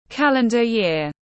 Năm dương lịch tiếng anh gọi là calendar year, phiên âm tiếng anh đọc là /ˈkæl.ən.də ˌjɪər/
Calendar year /ˈkæl.ən.də ˌjɪər/